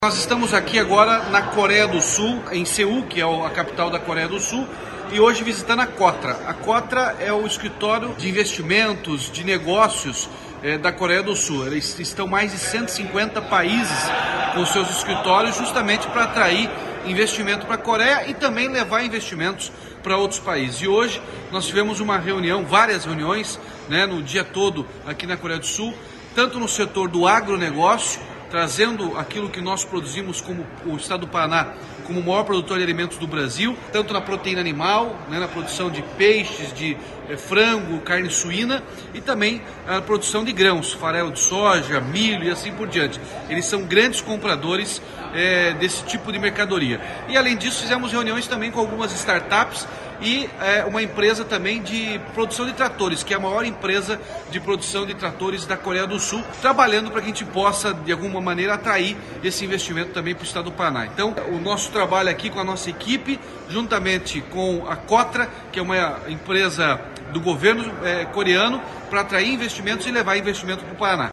Sonora do governador Ratinho Junior sobre visita a sede da Kotra na Coreia do Sul | Governo do Estado do Paraná